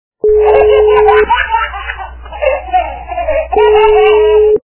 » Звуки » Смешные » Смех - Детский
При прослушивании Смех - Детский качество понижено и присутствуют гудки.
Звук Смех - Детский